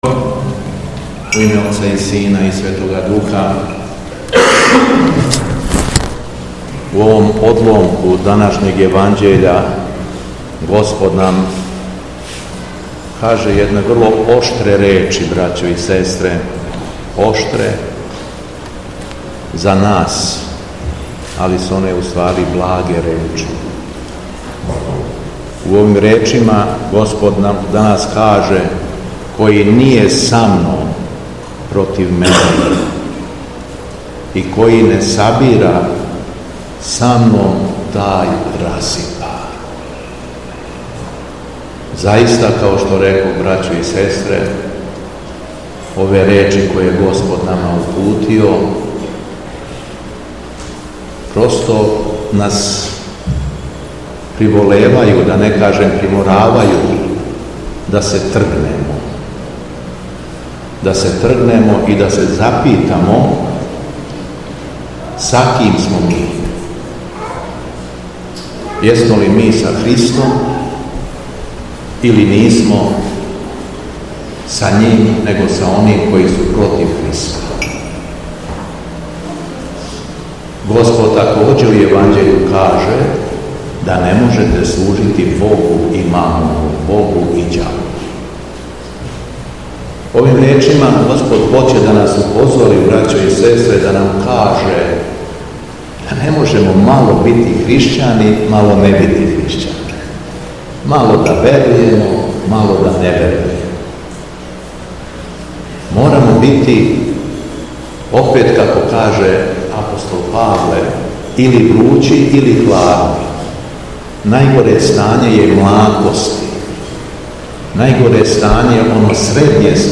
Беседа Његовог Високопреосвештенства Митрополита шумадијског г. Јована
Митрополит Јован је произнео свеју беседу након читања Светога Јеванђеља: